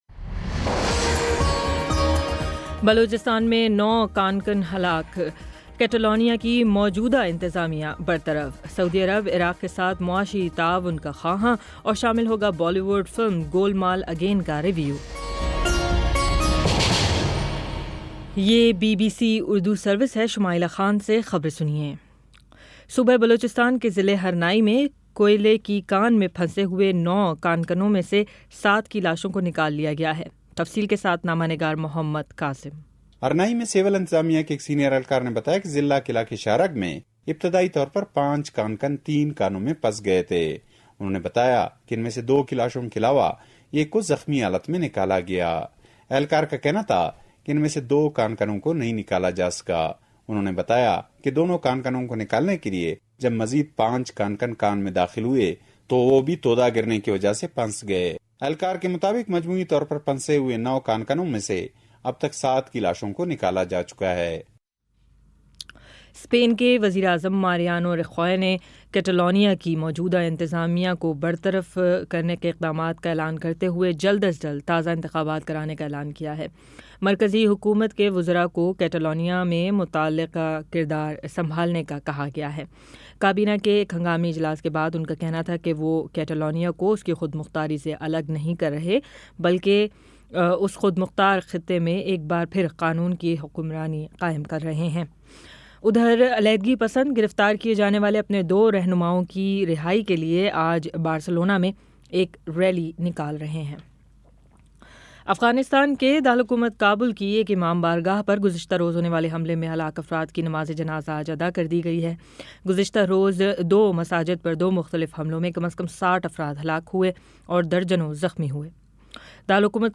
اکتوبر 21 : شام چھ بجے کا نیوز بُلیٹن